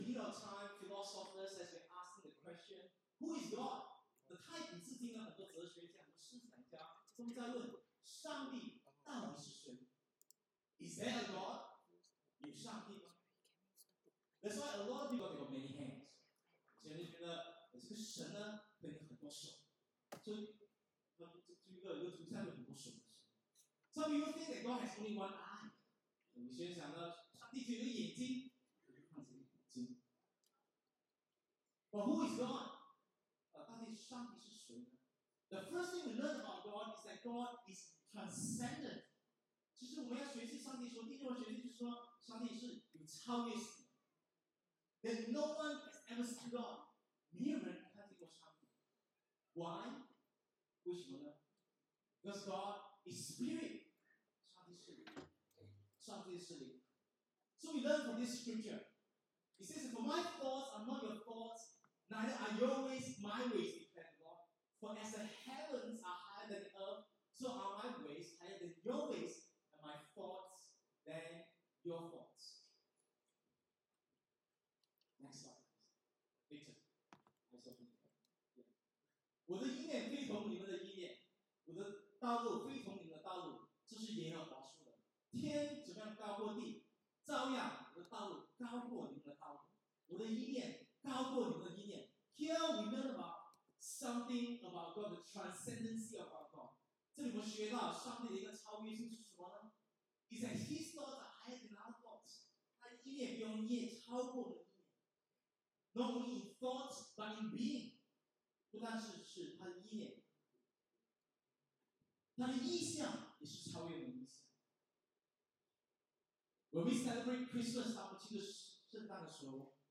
English Sermons | Casey Life International Church (CLIC)
Bilingual Worship Service - 25th December 2022